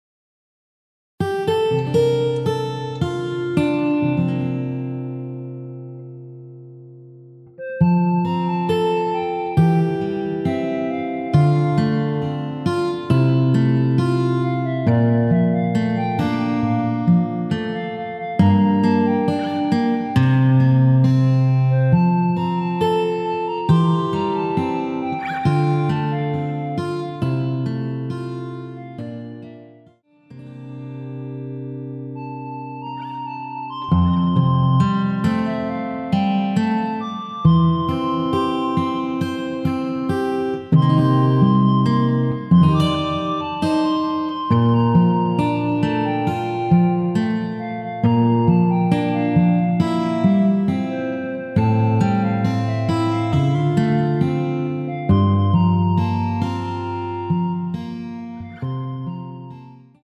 여자키 멜로디 포함된 MR입니다.(미리듣기 참조)
앞부분30초, 뒷부분30초씩 편집해서 올려 드리고 있습니다.
중간에 음이 끈어지고 다시 나오는 이유는
(멜로디 MR)은 가이드 멜로디가 포함된 MR 입니다.